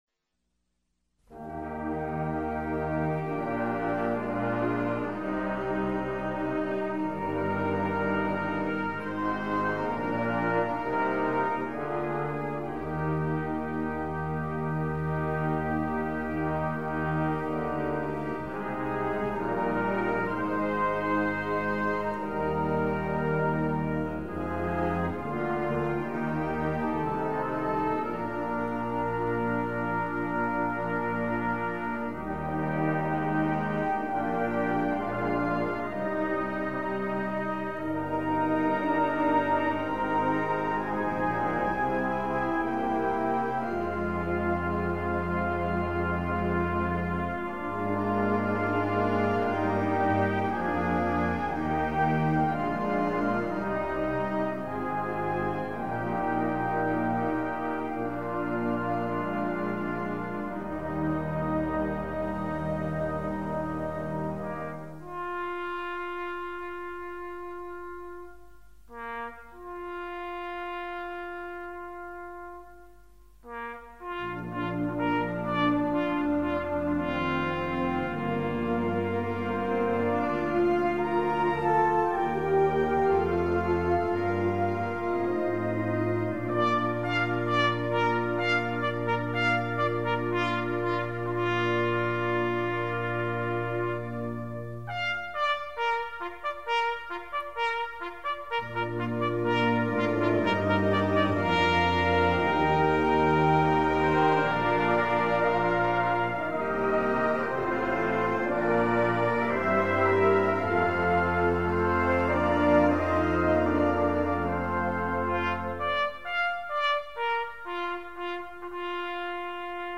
"Setting the Watch" was a bugle call, which began the tattoo period calling Canadian troops home to barracks.
bandoftheroyalmarinesabidewithmeandlastpost.mp3